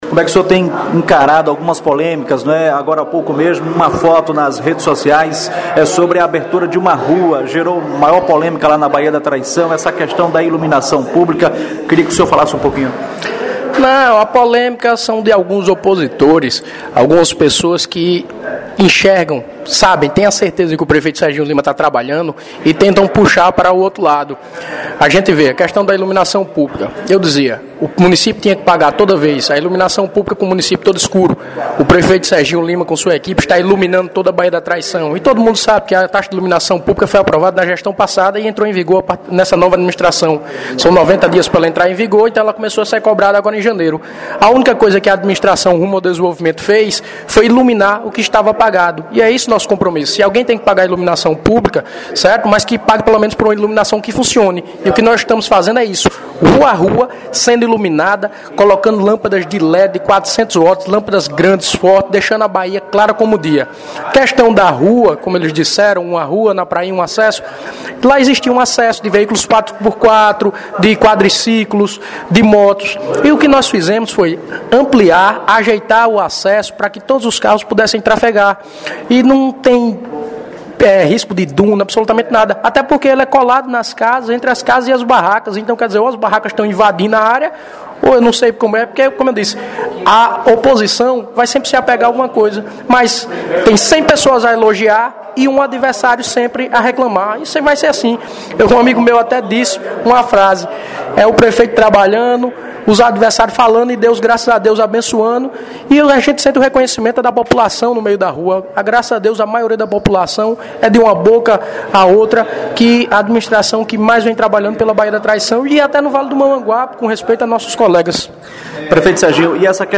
Entrevista
Na última quinta-feira (16) o prefeito Serginho Lima (PTB), concedeu entrevista à reportagem da Rádio Correio do Vale FM e abordou temas polêmicos que vem sendo debatidos pela população local e visitantes. Por exemplo: reajusta na taxa da iluminação pública; tratamento e reajuste da taxa de água e a cobrança dos veículos de turismo. O gestor também abordou os preparativos para os festejos carnavalescos.